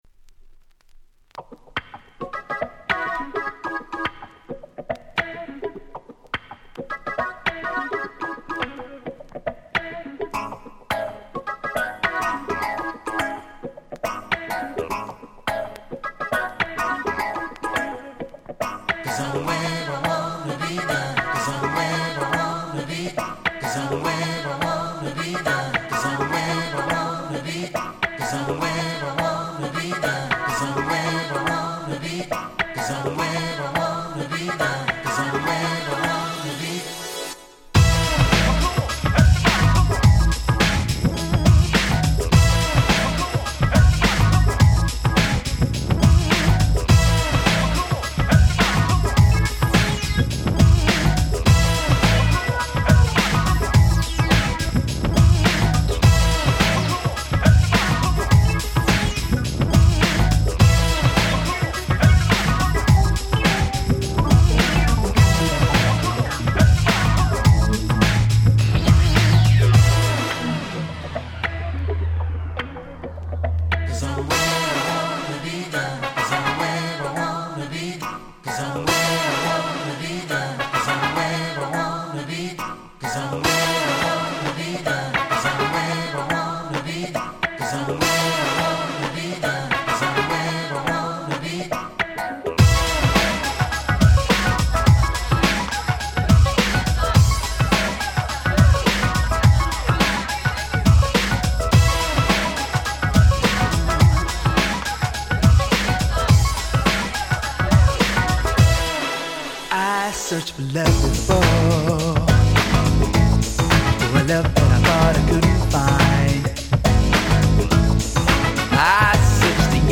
好みにもよるかとは思いますが、こちらの盤に収録のExtended〜は頭にブレイクがあり非常にMixし易いです！！
内容は言わずもがな、原曲に忠実ながらもキャッチー要素をしっかり兼ね備えた最高のカバーに仕上がっております！